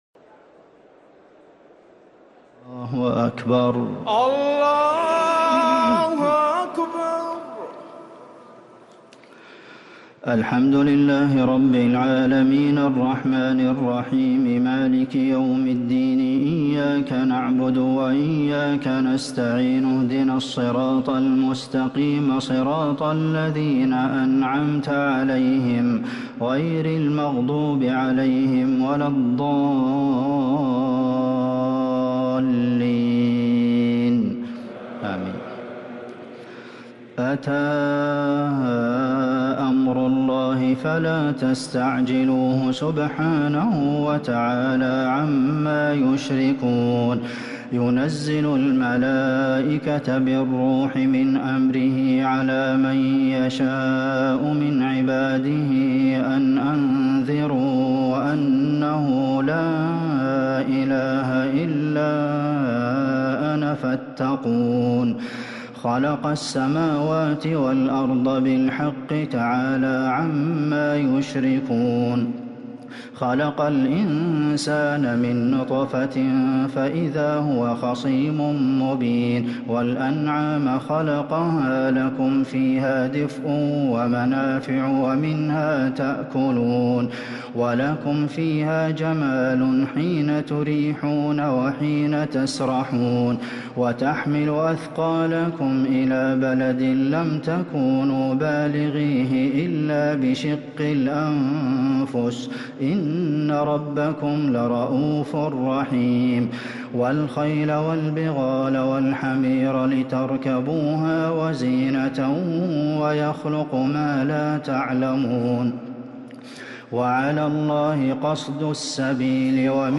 تراويح ليلة 18 رمضان 1444هـ فواتح سورة النحل(1-55) | taraweeh 18th night Ramadan 1444H Surah An-Nahl > تراويح الحرم النبوي عام 1444 🕌 > التراويح - تلاوات الحرمين